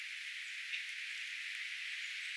tsak-ääni
Ehkä selvin äänistä.
Valittevasti paikalle tuuli kovasti,
mikä haittasi hiemen äänitystä.